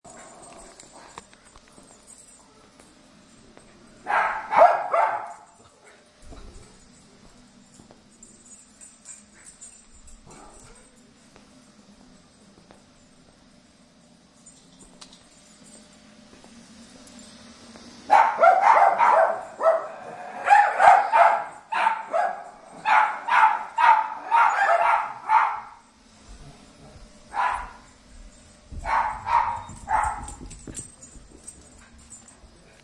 Barking Little Dogs In Cages Efecto de Sonido Descargar
Barking Little Dogs In Cages Botón de Sonido